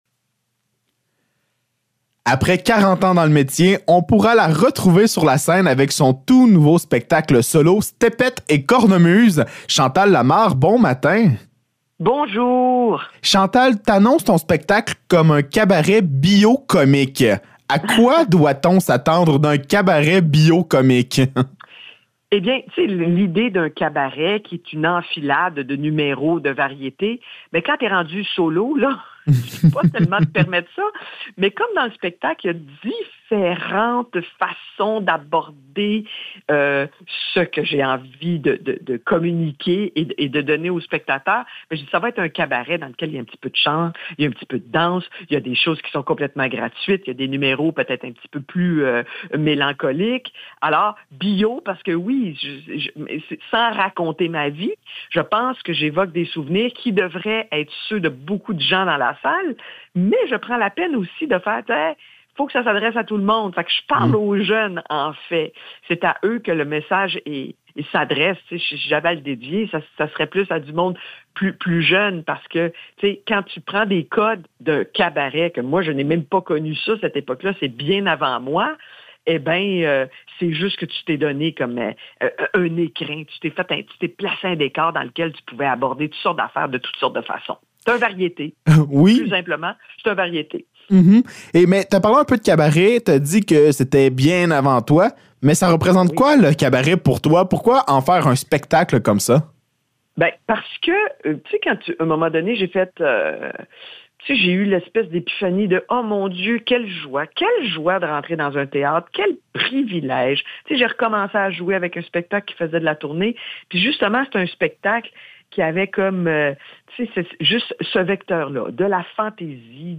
Entrevue avec Chantal Lamarre